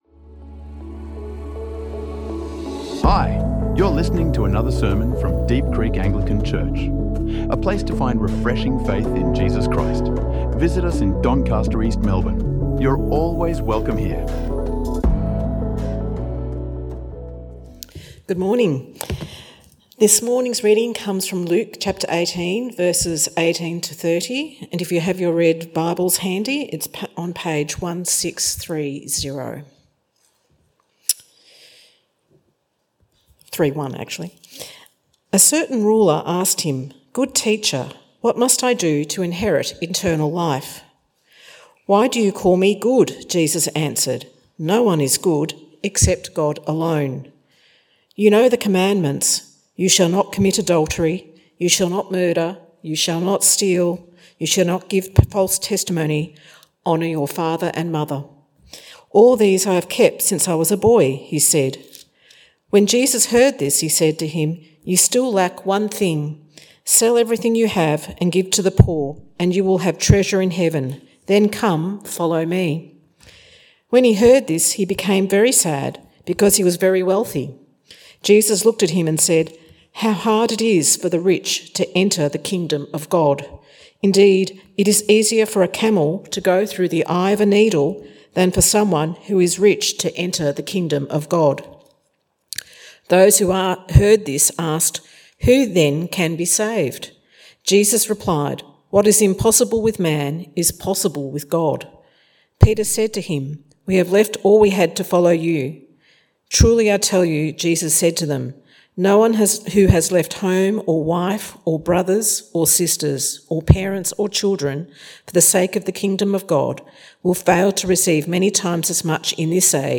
| Sermons | Deep Creek Anglican Church